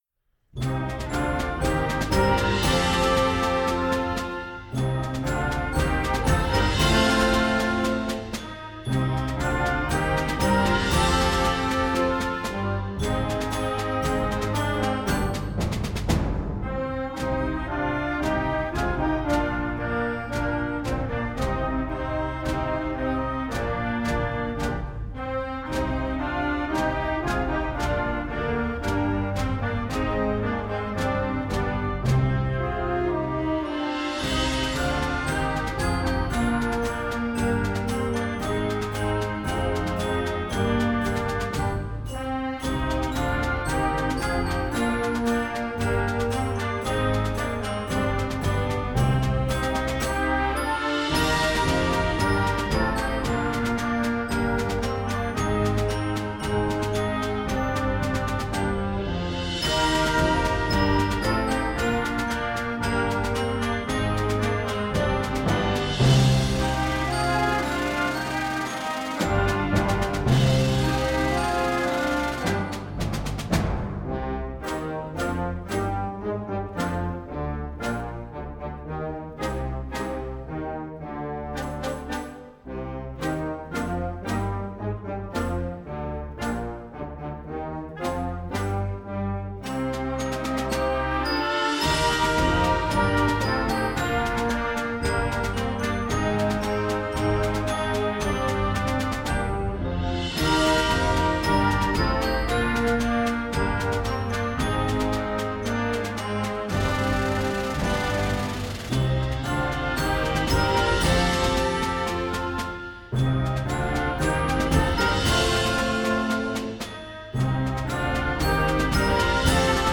Gattung: Weihnachtslied für flexibles Jugendblasorchester
Besetzung: Blasorchester